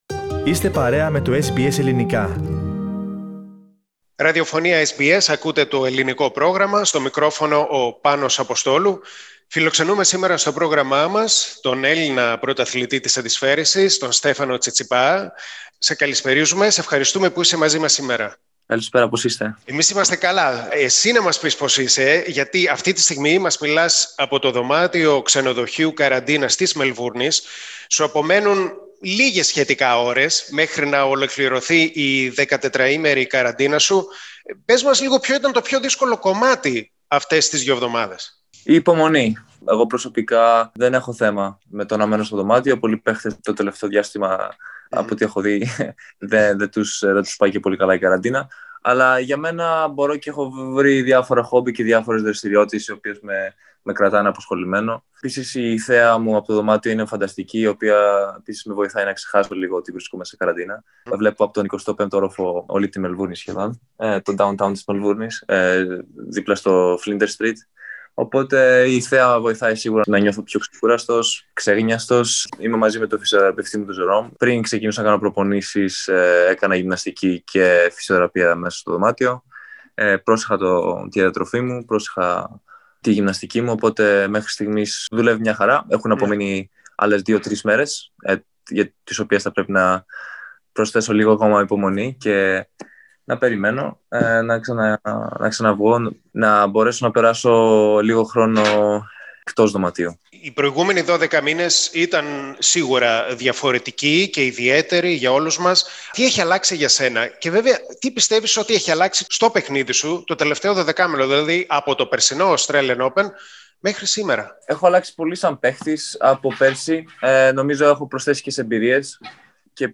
Λίγες ώρες πριν τη λήξη της 14ήμερης καραντίνας του σε ξενοδοχείο της Μελβούρνης, ο Έλληνας πρωταθλητής της αντισφαίρισης, Στέφανος Τσιτσιπάς, «ανοίγει» την καρδιά του και μιλά στο SBS Greek.